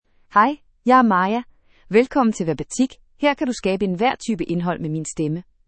FemaleDanish (Denmark)
Maya — Female Danish AI voice
Listen to Maya's female Danish voice.
Female
Maya delivers clear pronunciation with authentic Denmark Danish intonation, making your content sound professionally produced.